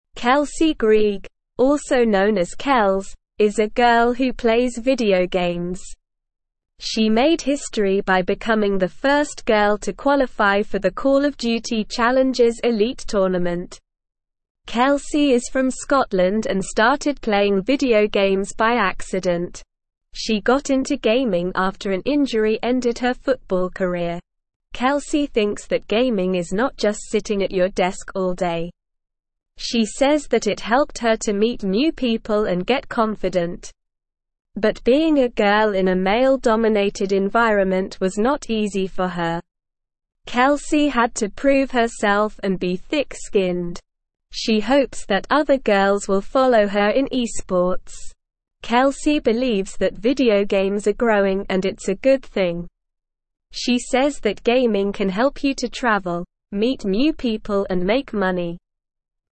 English-Newsroom-Beginner-SLOW-Reading-Girl-Makes-History-in-Video-Game-Tournament.mp3